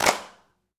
DrClap16.wav